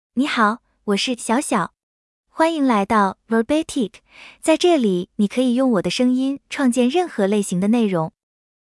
XiaoxiaoFemale Chinese AI voice
Xiaoxiao is a female AI voice for Chinese (Mandarin, Simplified).
Voice sample
Listen to Xiaoxiao's female Chinese voice.
Xiaoxiao delivers clear pronunciation with authentic Mandarin, Simplified Chinese intonation, making your content sound professionally produced.